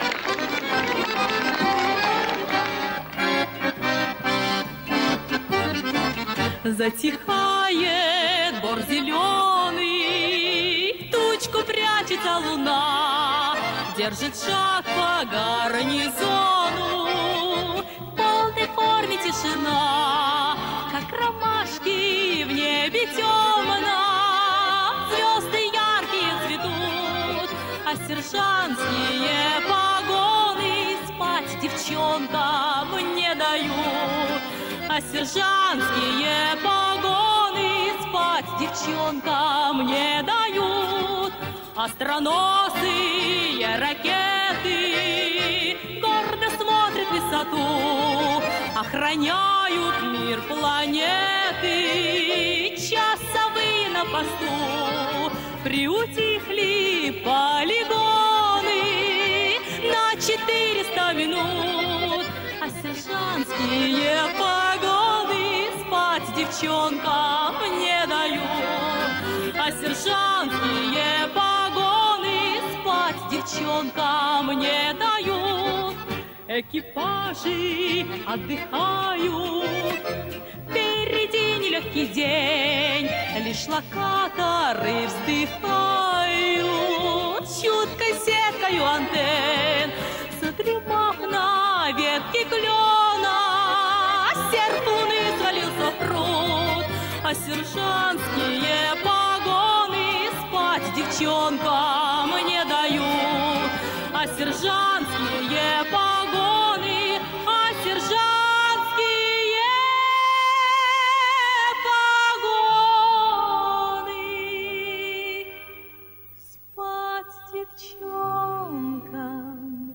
Другая вокальная школа.
Ко всем известным голосам не подходит.